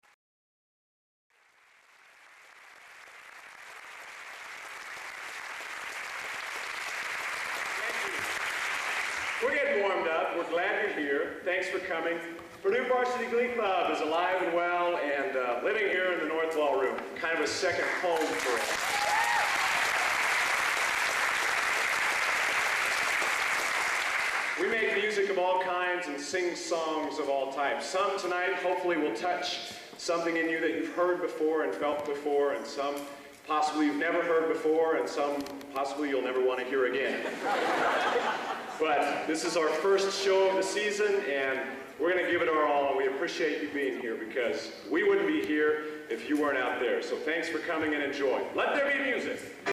Location: Purdue Memorial Union, West Lafayette, Indiana
Genre: | Type: Director intros, emceeing